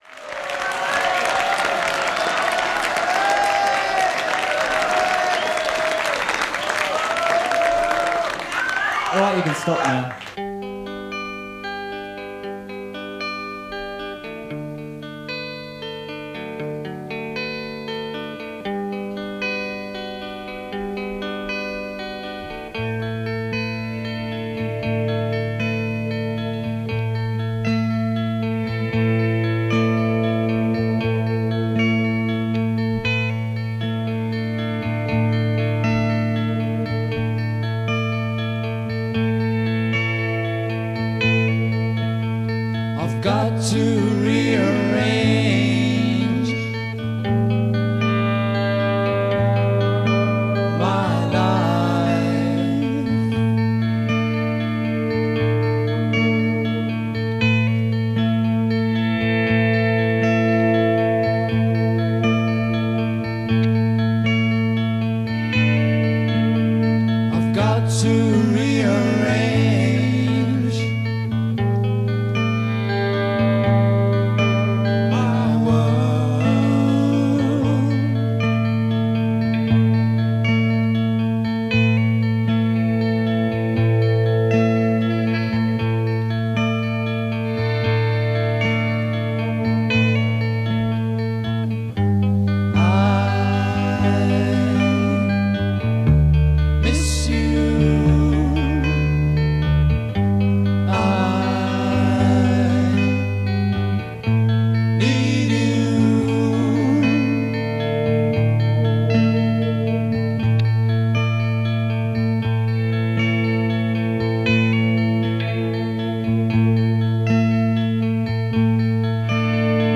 70s Hard-rock/Headbangers tonight.
bass & vocals
drums and percussion